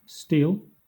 wymowa:
?/i enPR: stēl, IPA/stiːl/, SAMPA/sti:l/